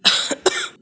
cough_1.wav